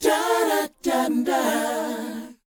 DOWOP F FD.wav